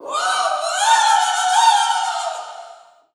Category: Ferocious, terrifying
tiếng hét đàn ông tiếng hét phụ nữ tiếng la hét